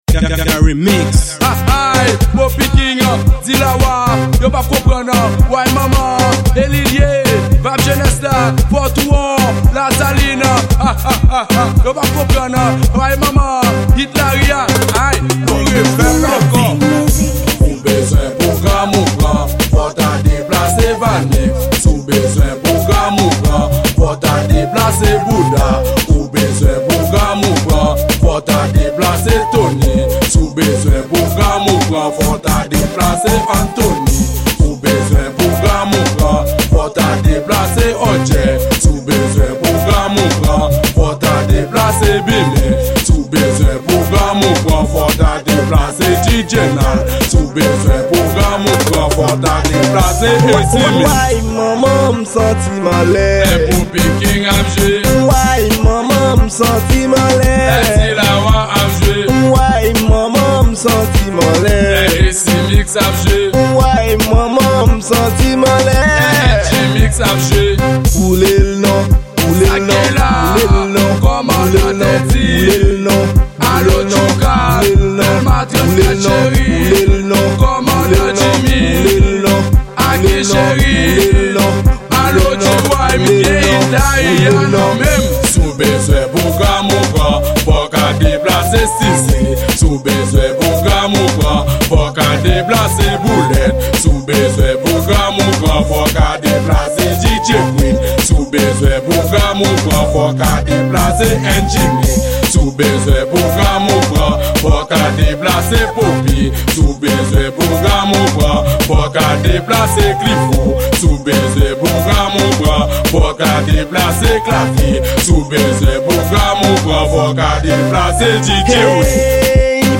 Genre: Raboday